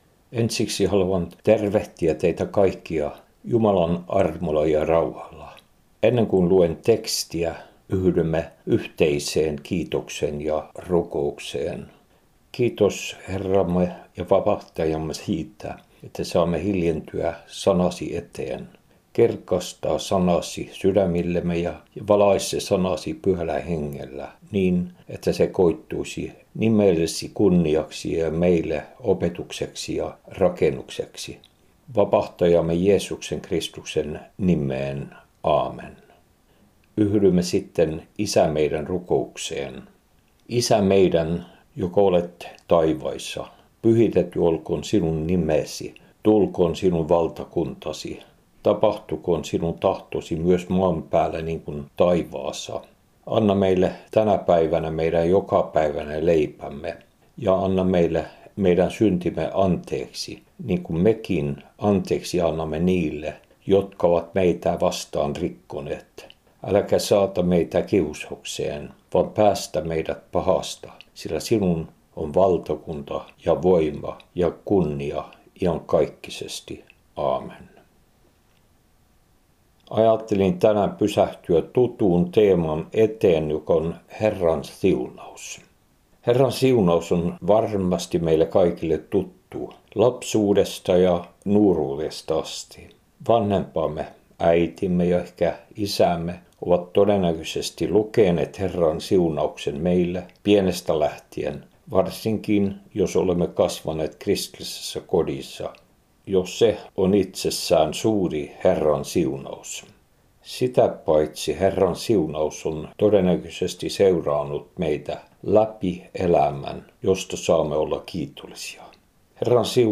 Radiohartaus Radio LFF:ssä 22.2.2023